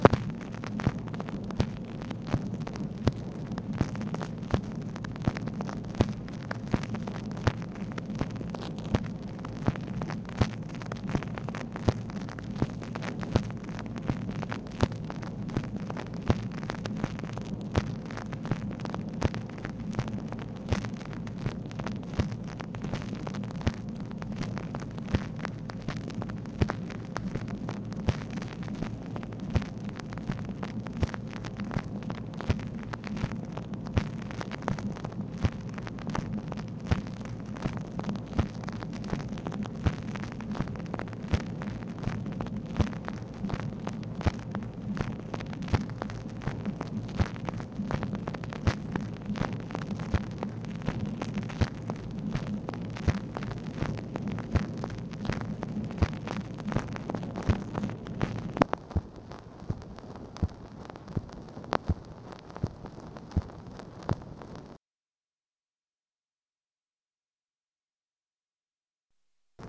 MACHINE NOISE, July 3, 1964
Secret White House Tapes | Lyndon B. Johnson Presidency